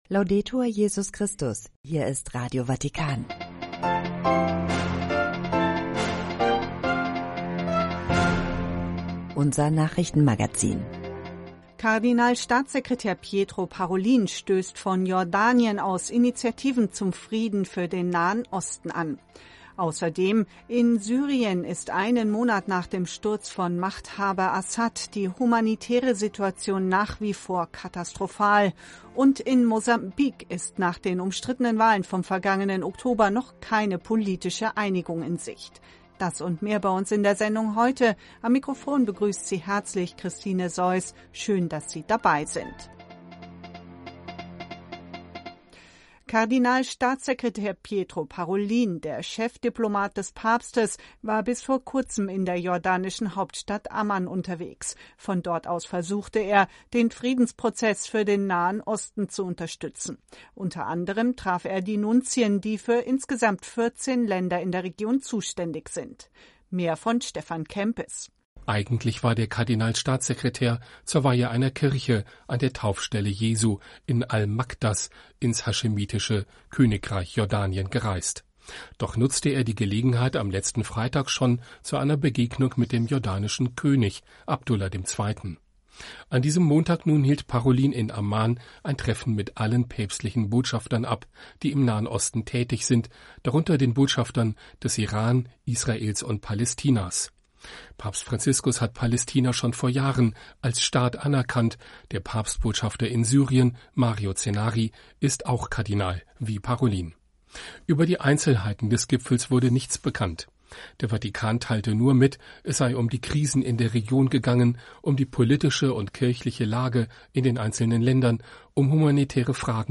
Treffpunkt Weltkirche - Nachrichtenmagazin (18 Uhr).